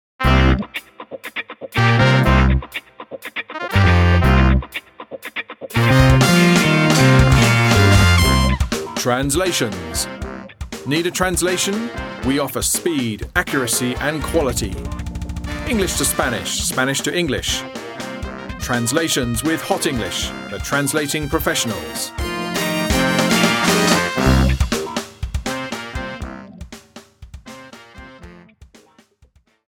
HE91 - trk 20 - Radio Ad - Translati.mp3